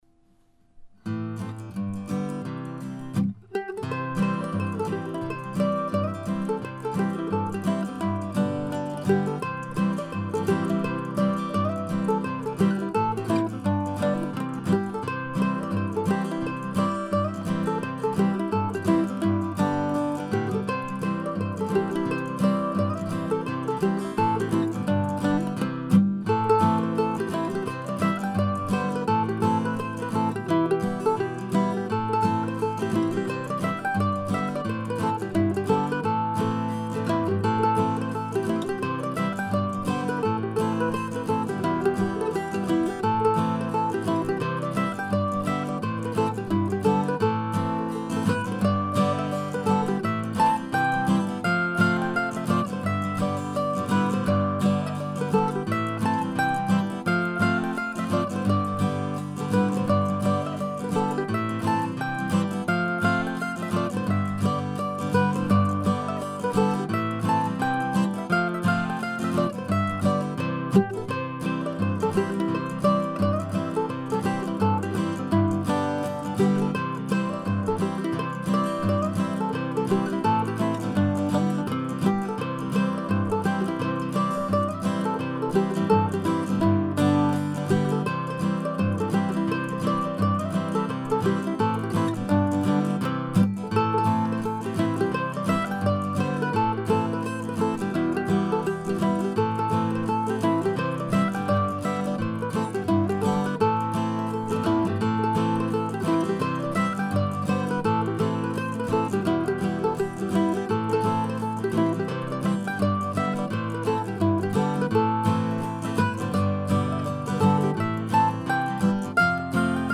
mp3 recording of the basic tune on mandolin and guitar)
You can see that I've added a bit to the original tune but that I haven't changed its fundamental character.)